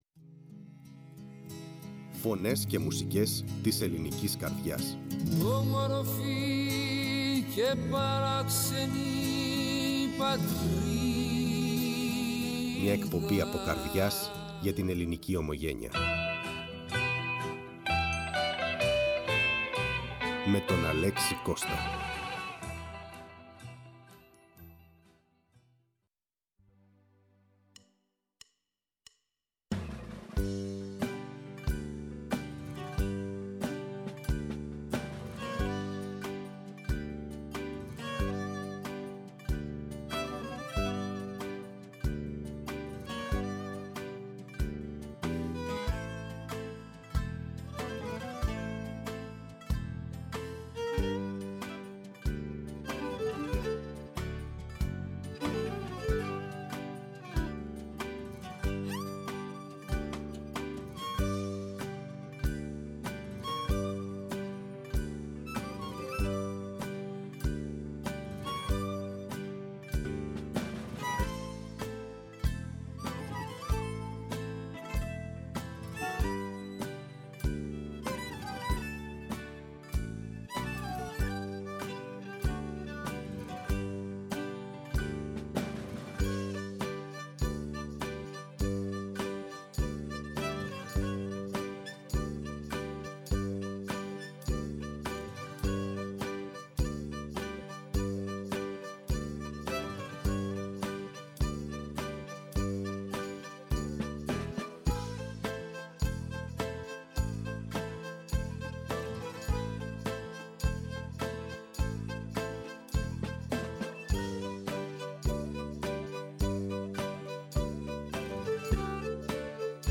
Η ΦΩΝΗ ΤΗΣ ΕΛΛΑΔΑΣ Φωνες και Μουσικες ΜΟΥΣΙΚΗ Μουσική ΟΜΟΓΕΝΕΙΑ ΣΥΝΕΝΤΕΥΞΕΙΣ Συνεντεύξεις Βερολινο ΜΟΥΣΙΚΟΣ